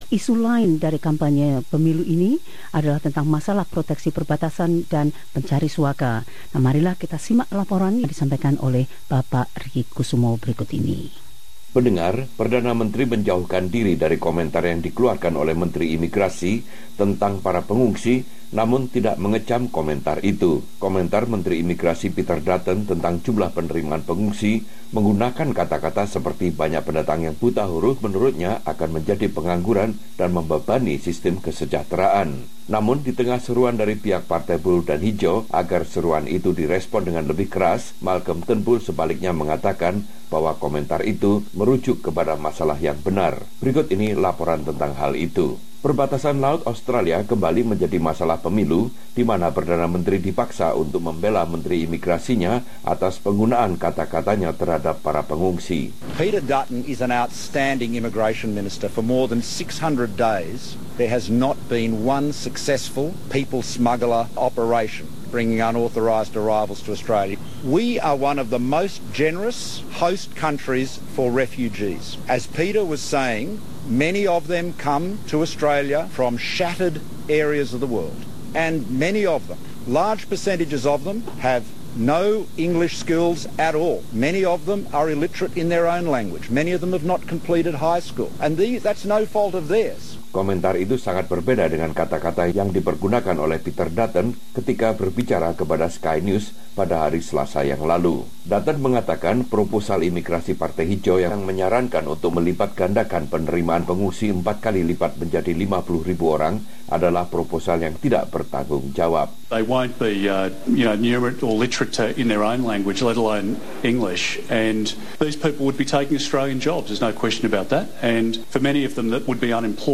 Laporan ini menyoroti isu utama yang muncul dalam hari ke 10 kampanye pemilu 2016.